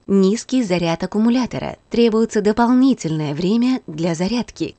Звуки робота-пылесоса